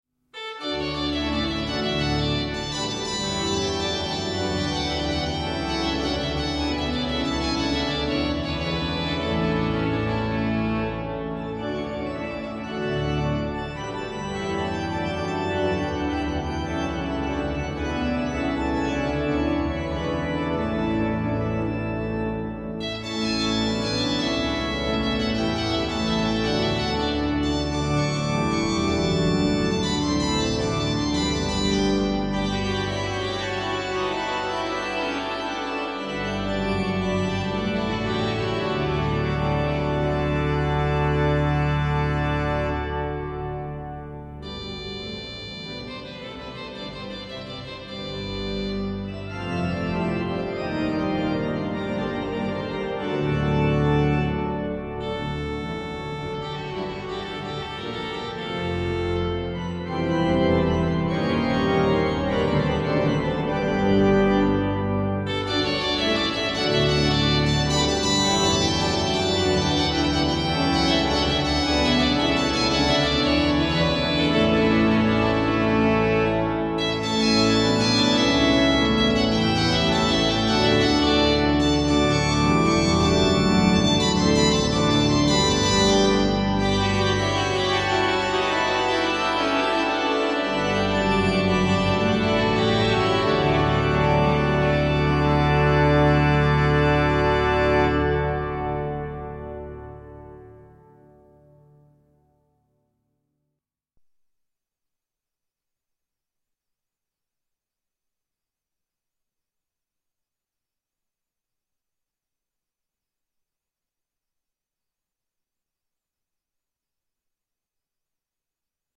Concierto de Otoño a la Luz de las Velas
on the new Allen Quantum organ at the Cathedral of Valladolidin in Northern Spain.
all live recorded and published on this page.